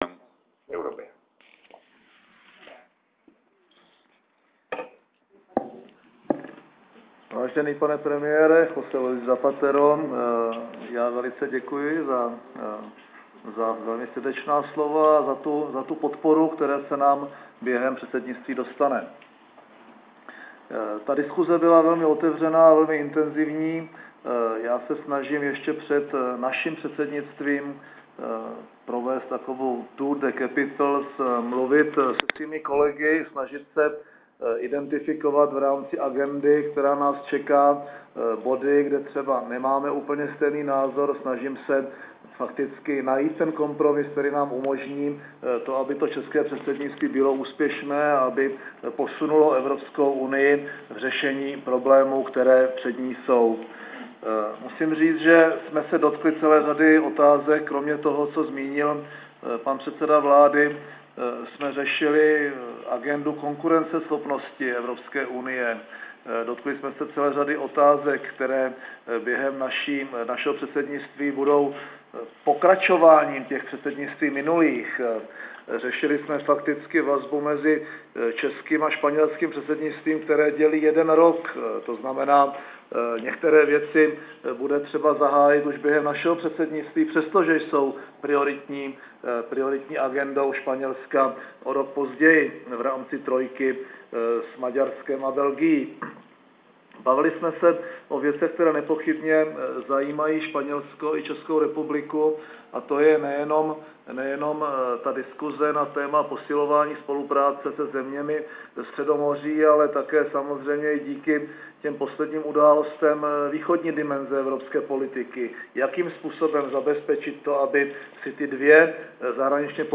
Společná tisková konference premiéra M. Topolánka a španělského premiéra J. L. Zapatera
Audiozáznam tiskové konference premiéra M. Topolánka po setkání s J. L. Zapaterem